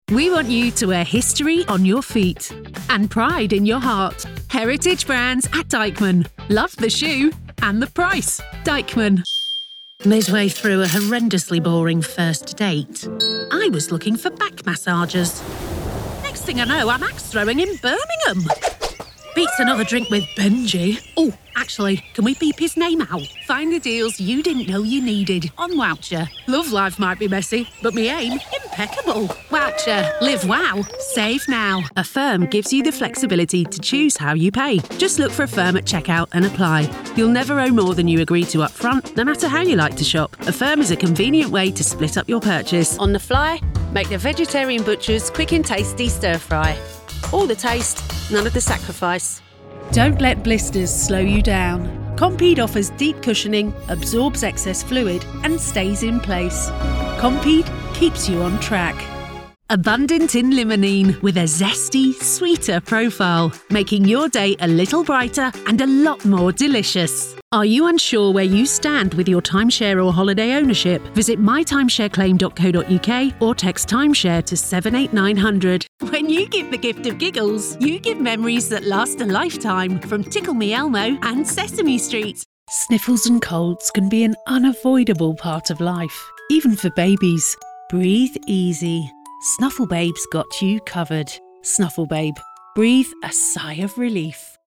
From warm, smooth and classy to fresh, upbeat and natural.
Commercial Demo
Middle Aged
I have my own professional home studio and can deliver a fast turnaround between 24-48 hours.